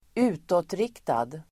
Uttal: [²'u:tåtrik:tad]